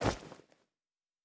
Bag 01.wav